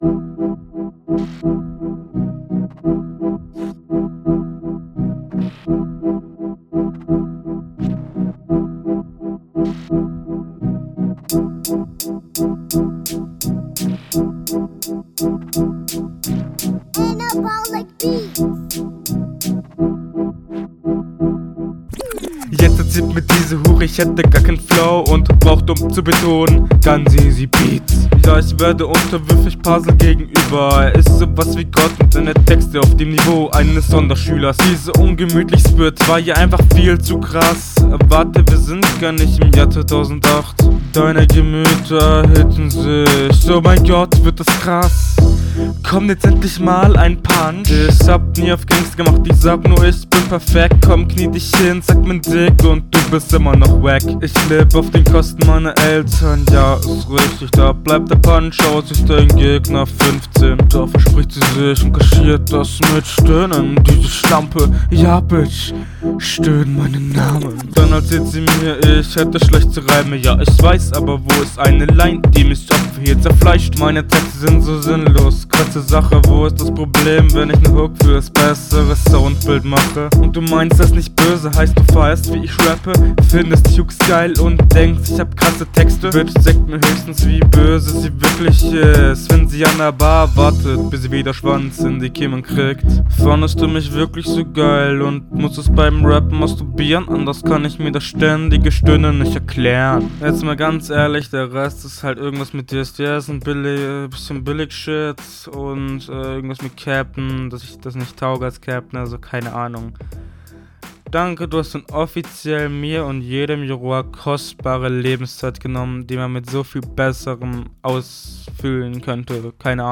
Soundbild besser, auch wenn der Beat einen Tacken zu laut ist, stimmt es hier …